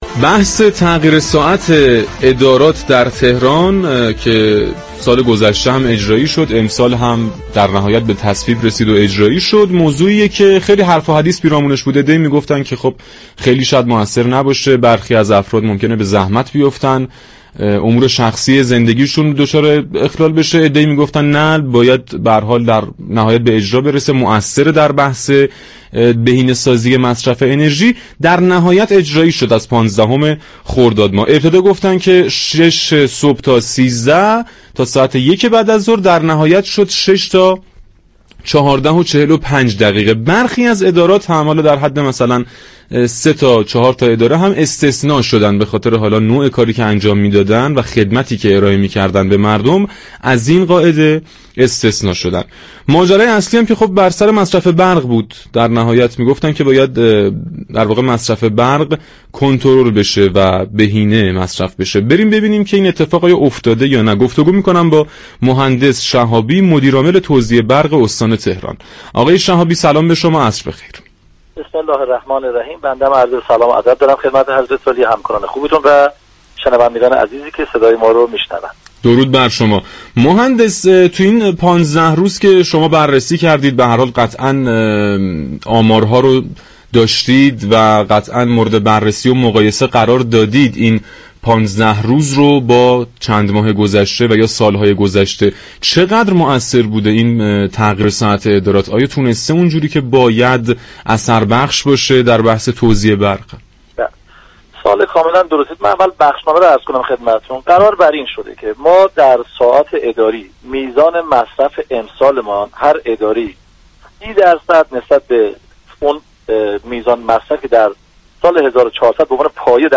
در گفت و گو با «تهران من»
در پاسخ به پرسش یكی از شنوندگان برنامه